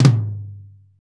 TOM P C HM1C.wav